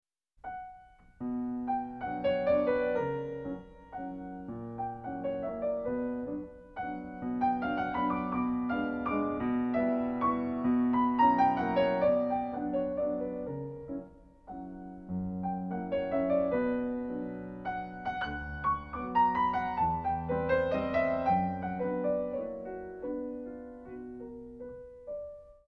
Pianist
refined, patrician touch